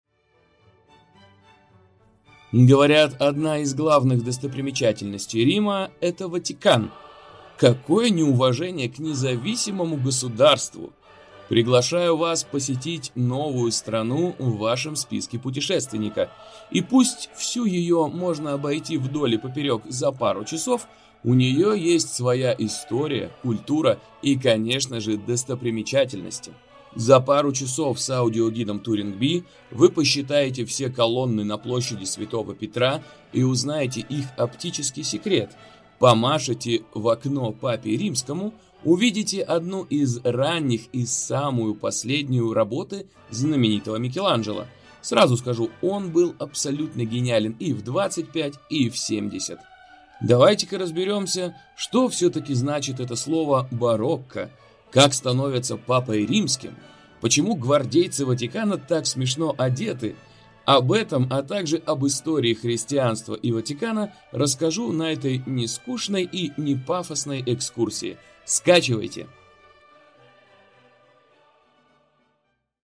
Лёгкая прогулка с аудиогидом TouringBee подскажет, что посмотреть в Ватикане, если цель — рассмотреть Собор Святого Петра не на открытке.